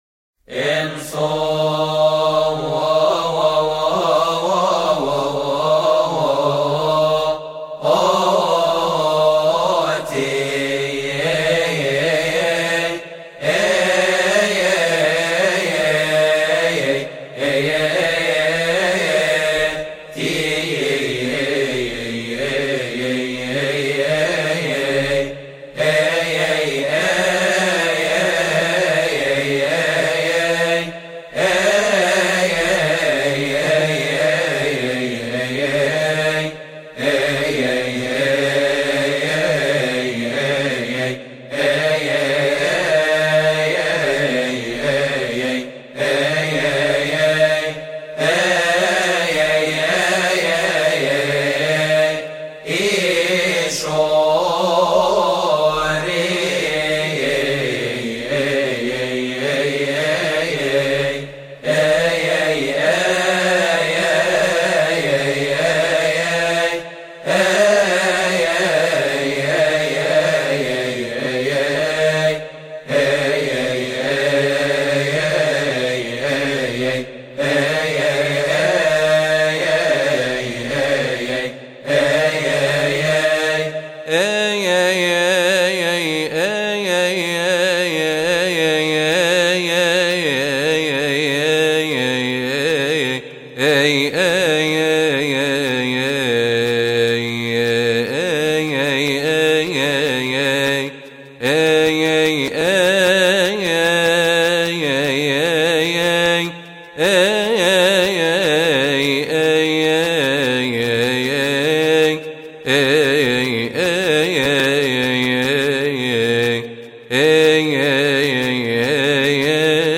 06 لحن إنثو تي تي شوري الصيامي.mp3